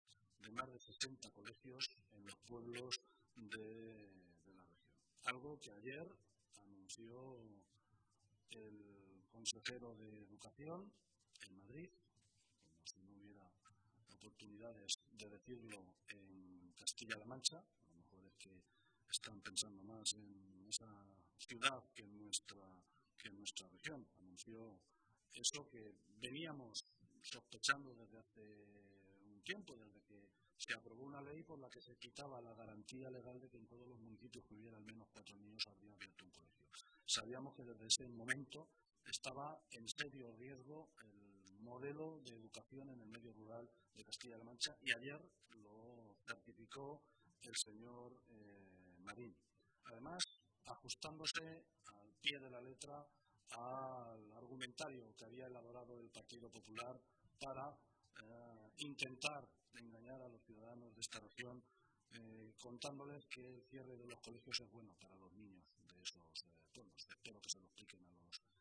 Así lo ha manifestado esta mañana, en rueda de prensa, el portavoz del Grupo Socialista, José Luis Martínez Guijarro.